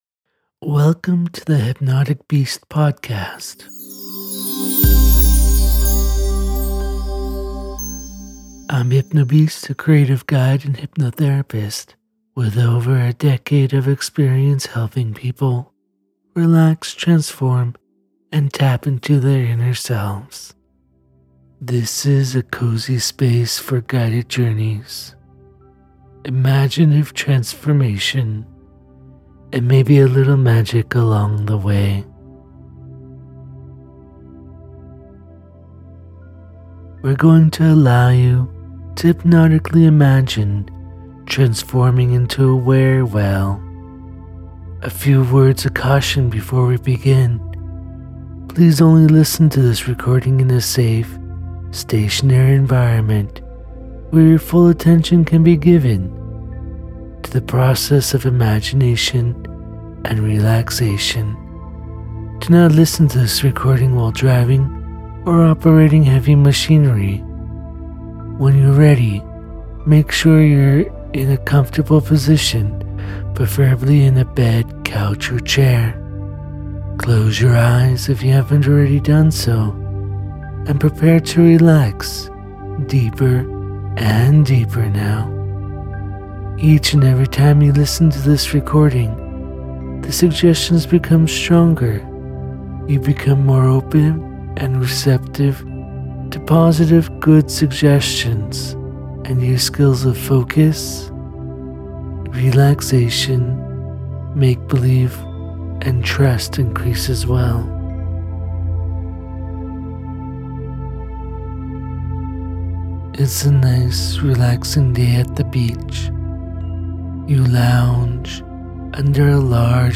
This guided imagery takes you on a serene journey by the sea, where you slowly release tension and drift into the rhythm of the waves.